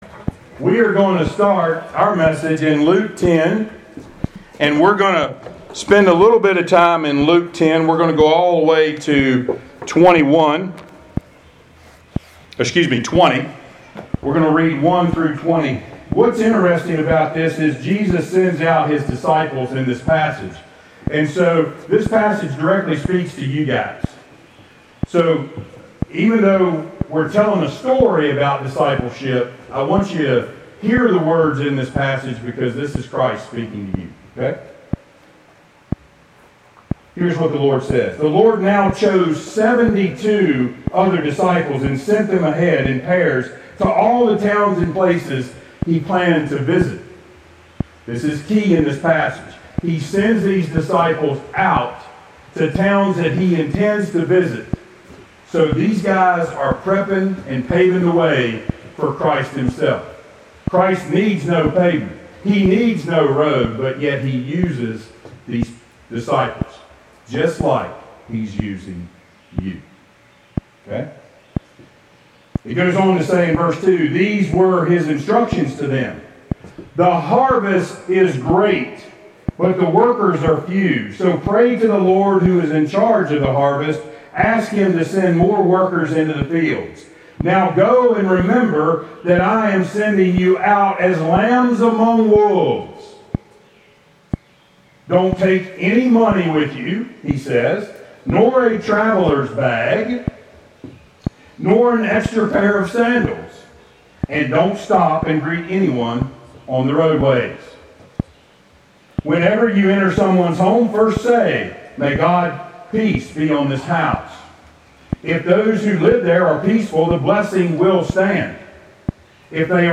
Passage: Luke 10:1-20 Service Type: Sunday Worship